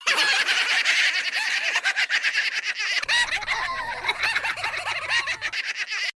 В коллекции есть разные варианты: от шороха лапок до стрекотания.
Смех тараканов из мультфильма